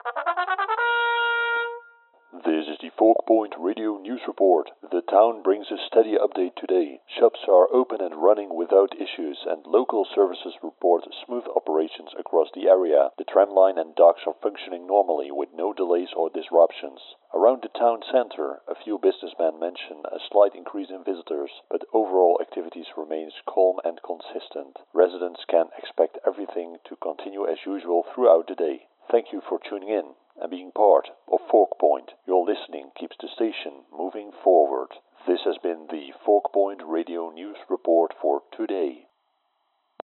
Broadcast recording: News Report — November 21, 2025